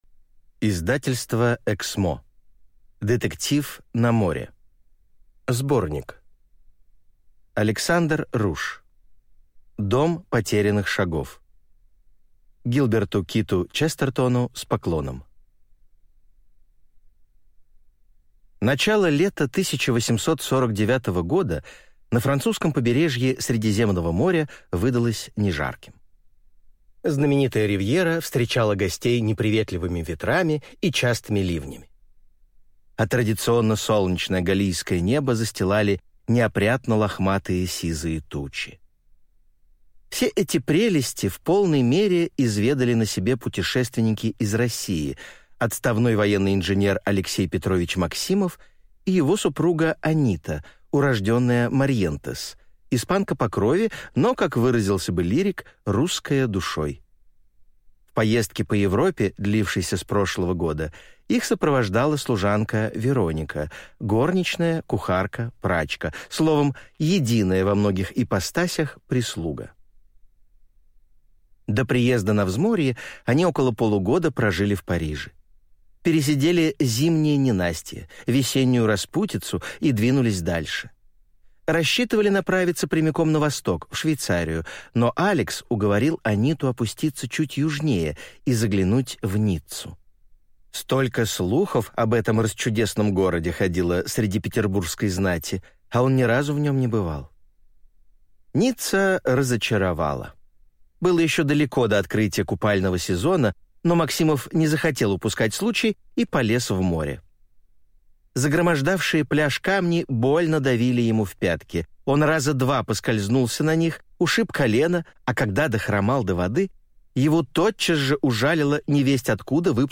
Аудиокнига Детектив на море | Библиотека аудиокниг